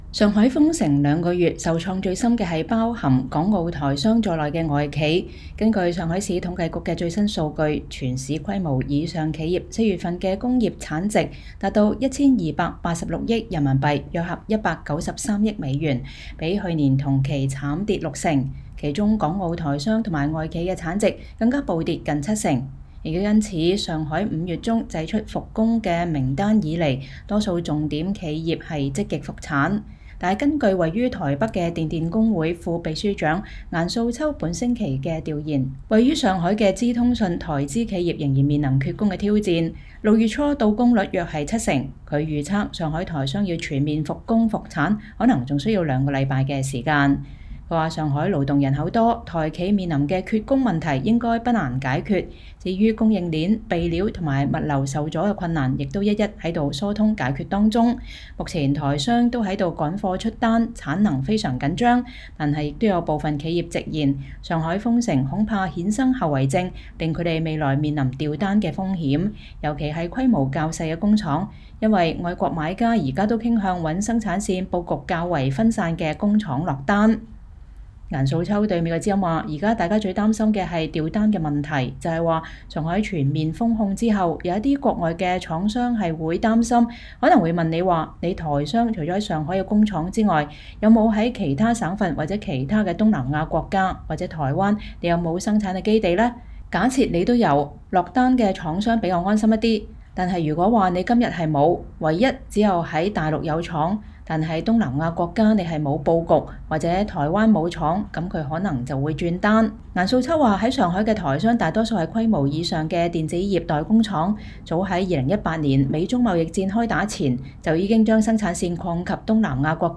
一位因議題敏感不願透露姓名的上海經濟學家在接受美國之音採訪時表示，上海才剛踏出解封的第一步，未來經濟復甦的力道尚言之過早，尤其，上海還不算全面解封，因為近幾日一旦發現確診案例，多個小區還是馬上遭封。